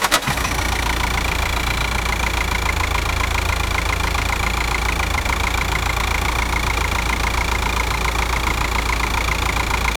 Idle engine, mercedes amg gtr
idle-engine-mercedes-amg--gzxrjhgb.wav